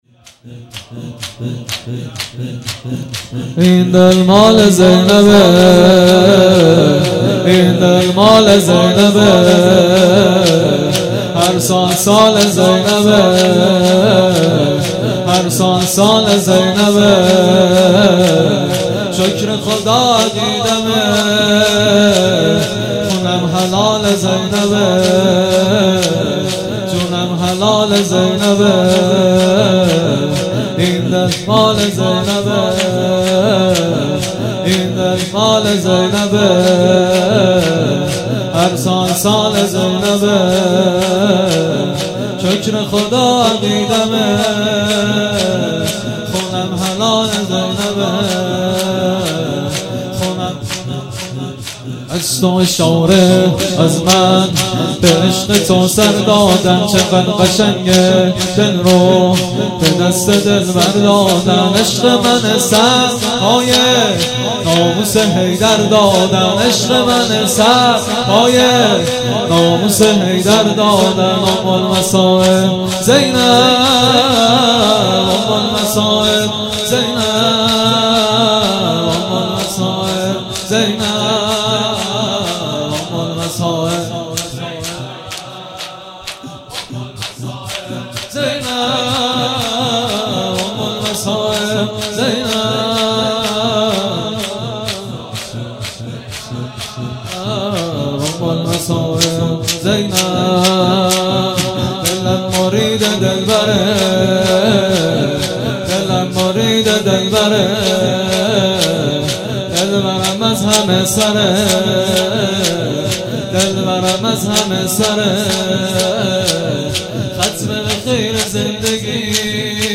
شور
دهه سوم محرم